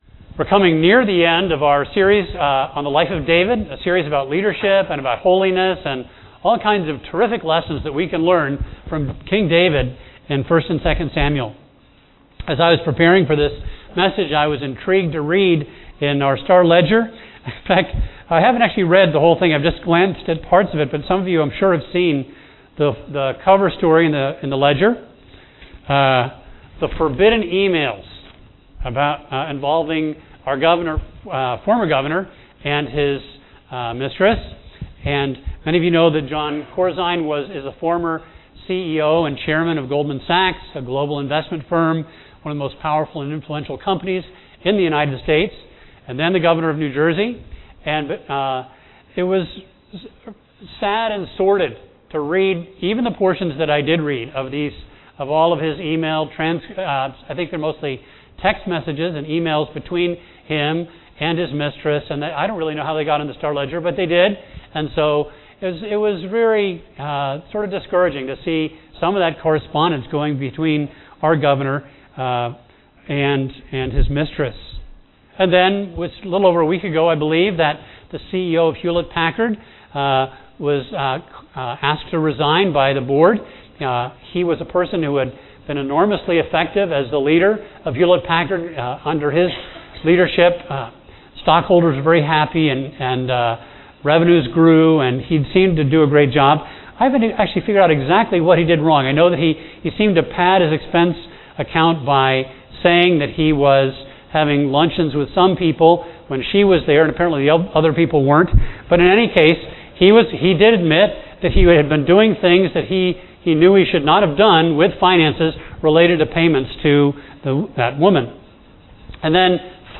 A message from the series "David."